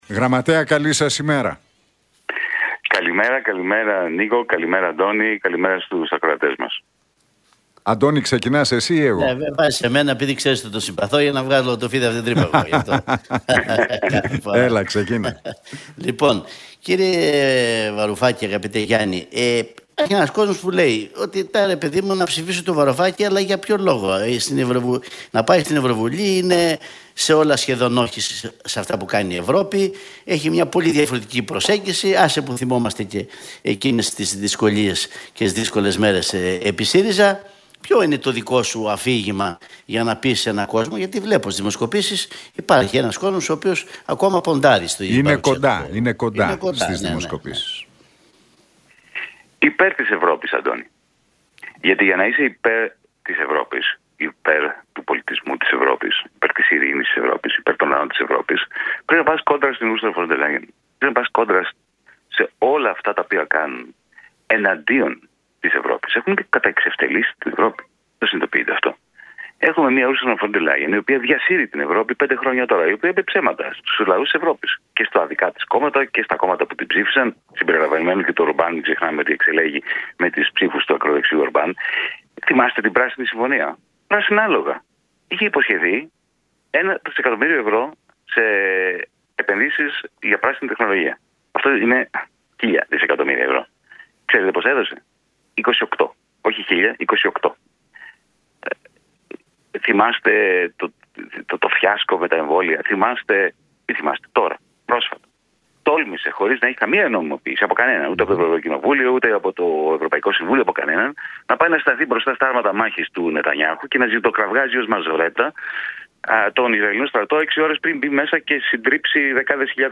Βαρουφάκης στον Realfm 97,8: Εγκληματίας εναντίον της λογικής ο Μητσοτάκης – Θα πάμε υπέρ της Ευρώπης κόντρα στην Φον Ντερ Λάιεν